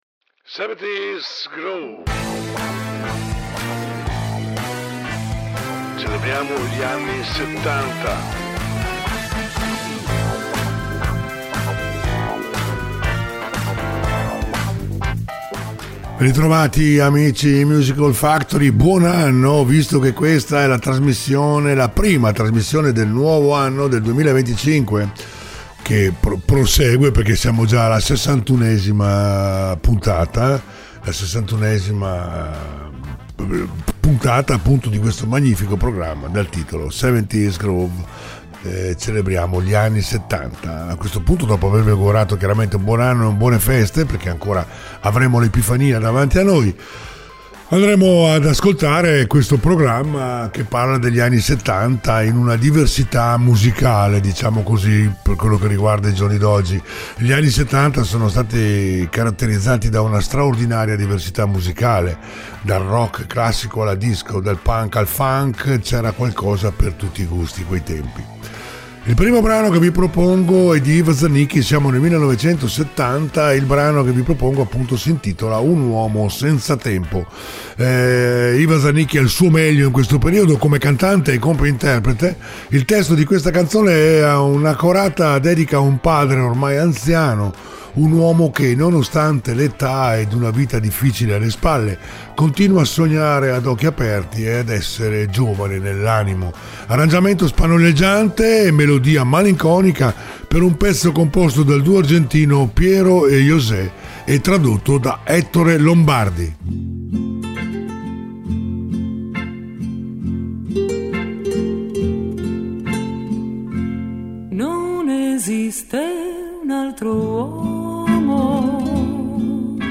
70's Groove